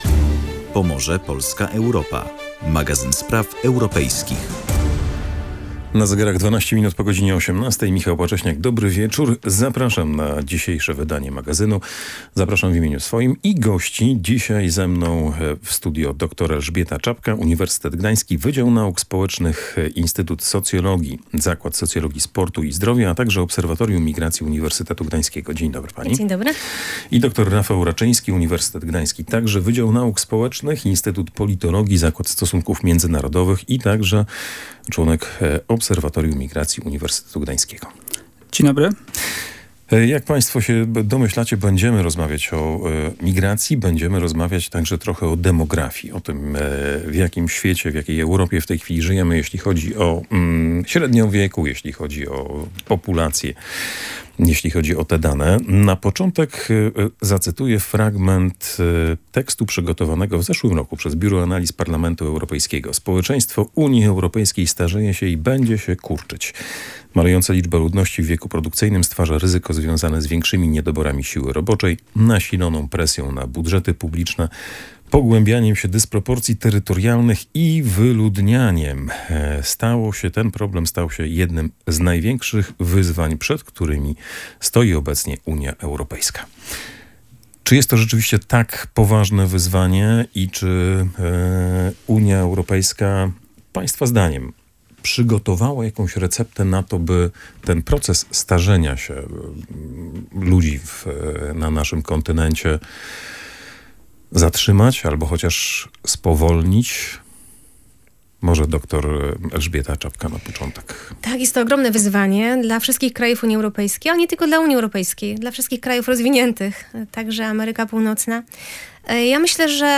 Na te pytania odpowiadali goście audycji „Pomorze, Polska, Europa”